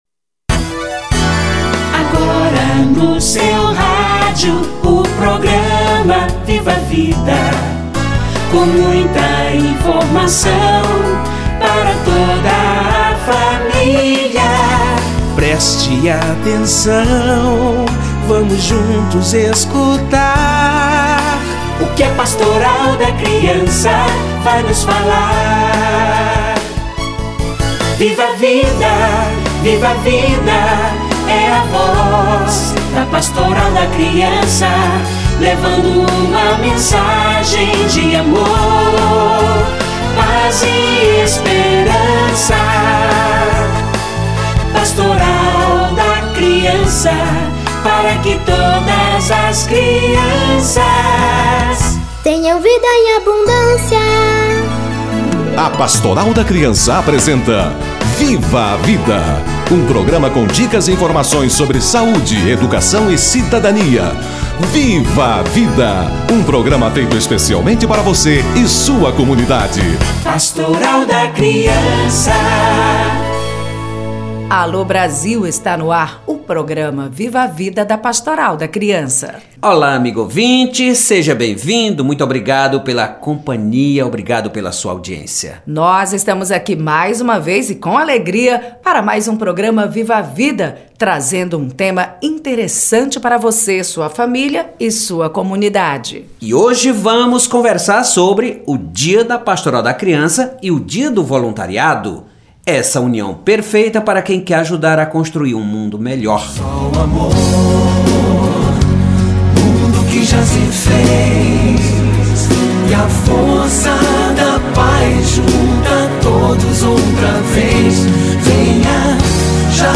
Dia da Pastoral da Criança / Dia Internacional do Voluntário - Entrevista